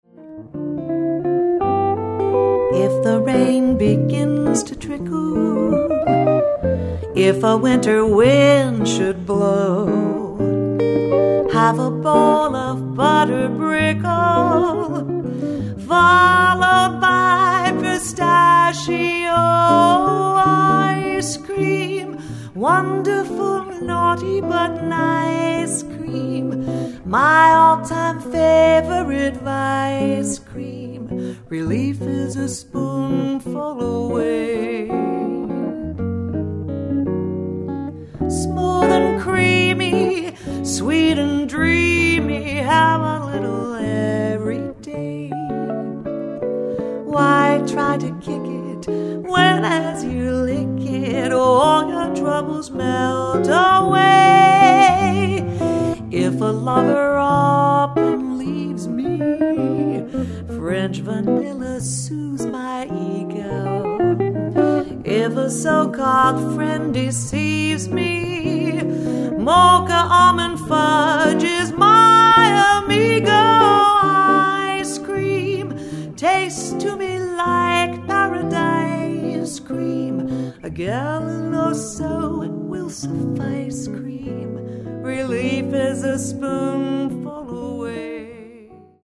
playful  ditty